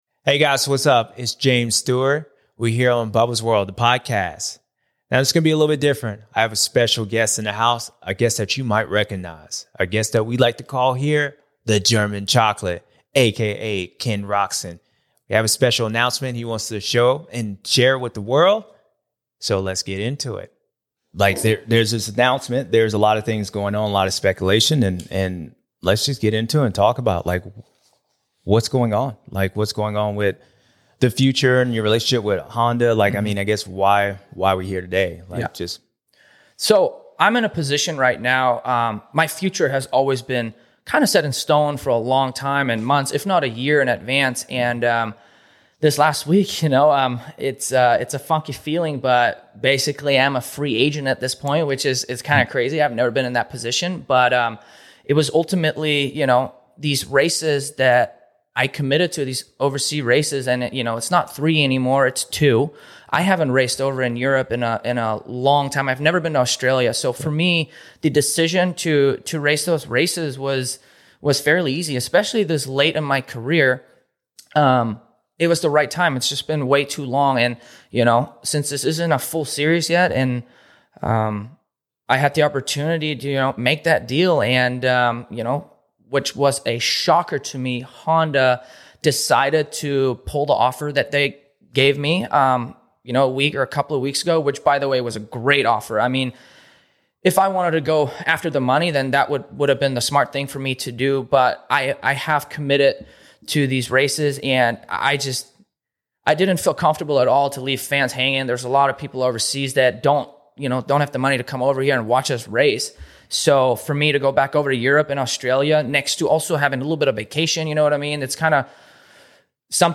KEN ROCZEN EXCLUSIVE INTERVIEW // Now A Free Agent